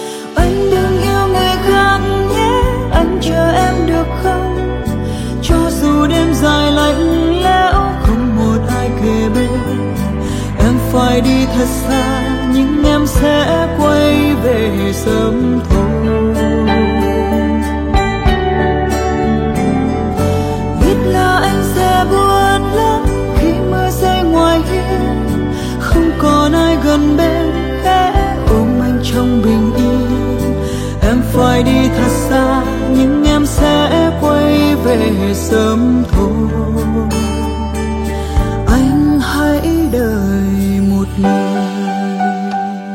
thể loại Nhạc Trẻ